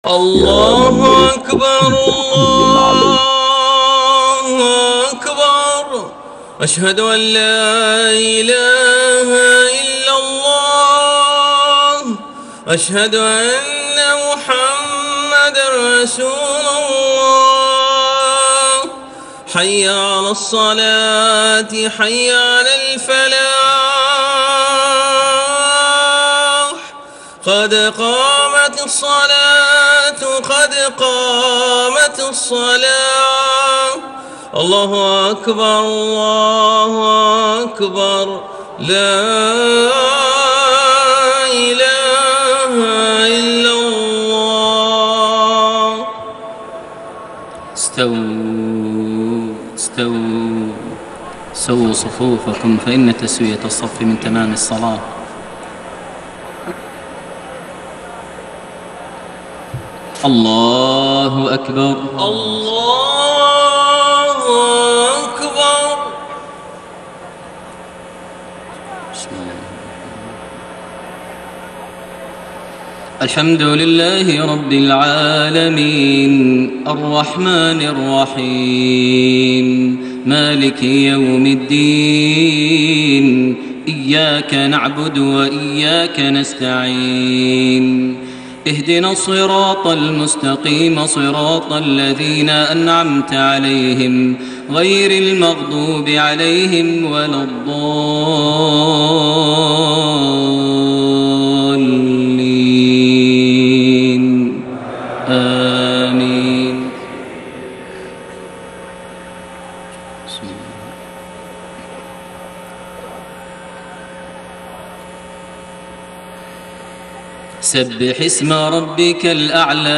صلاة العشاء 1 شوال 1432هـ سورتي الأعلى و الغاشية > 1432 هـ > الفروض - تلاوات ماهر المعيقلي